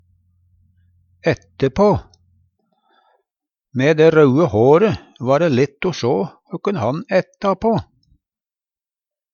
DIALEKTORD PÅ NORMERT NORSK ætte på slekte på Eksempel på bruk Mæ dæ raue håre va dæ lett o sjå høkken han ætta på.